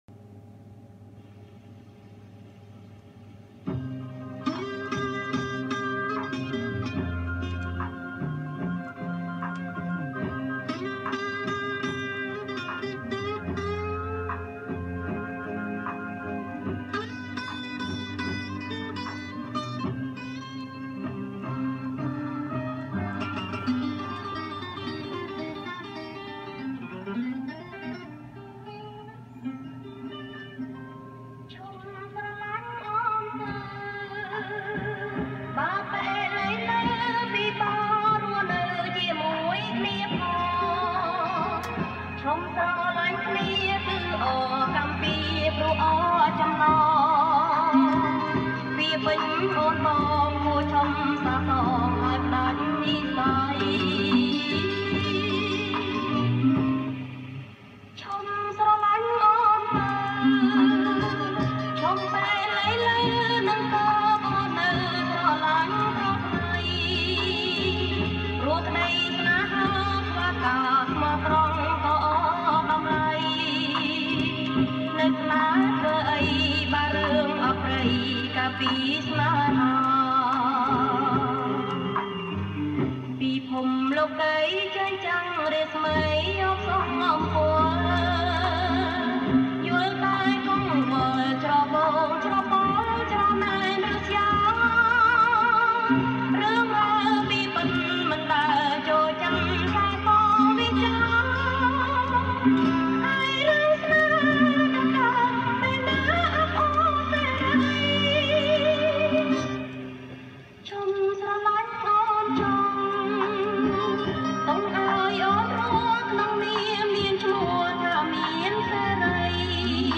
ប្រគំជាចង្វាក់ Bolero lent